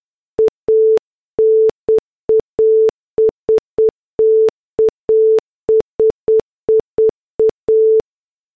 描述：这种声音完全是模拟的。
为了创造这种声音，我在440赫兹产生了一个正弦曲线，然后我播放了持续时间和静音以再现摩尔斯电码的声音。这是一个非常简单的声音。
Tag: 莫氏 奥妙 无线电 代码 莫尔斯电码